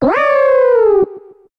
Cri de Mamanbo dans Pokémon HOME.